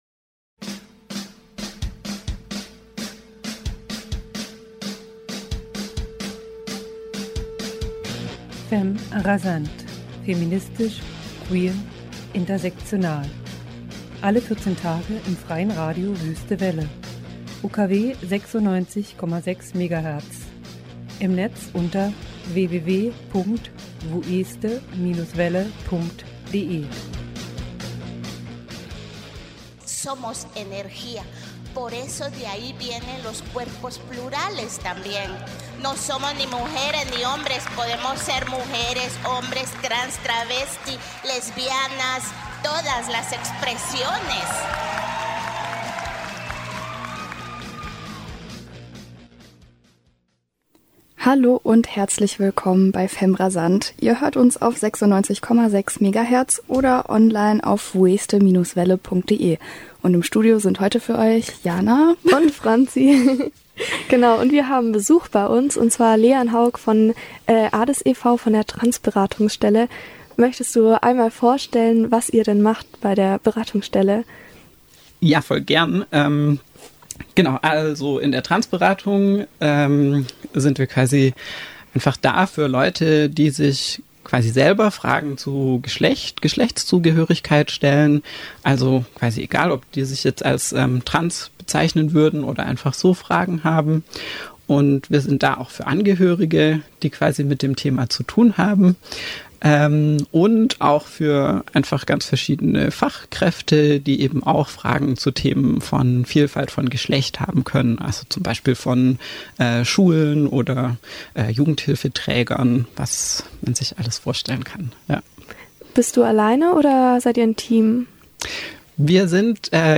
Das Interview vom 23. Januar zum nachhören